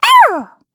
Taily-Vox_Attack5_kr.wav